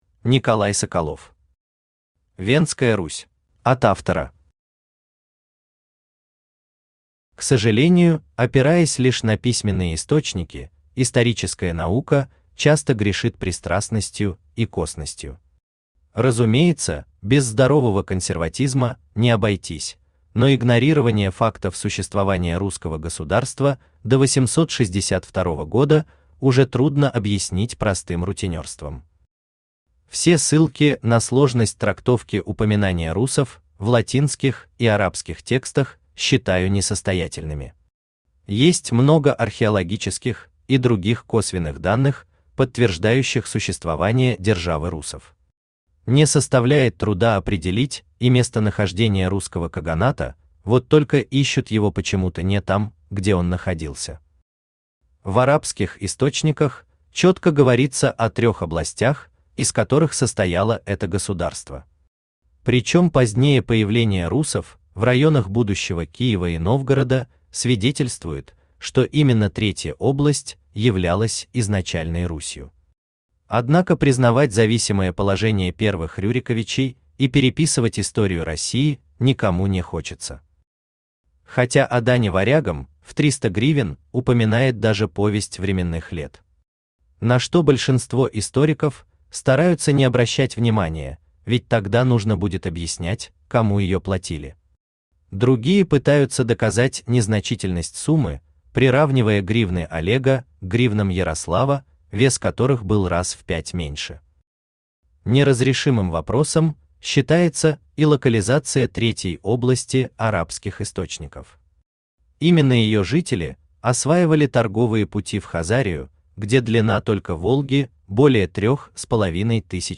Аудиокнига Вендская Русь | Библиотека аудиокниг
Aудиокнига Вендская Русь Автор Николай Васильевич Соколов Читает аудиокнигу Авточтец ЛитРес.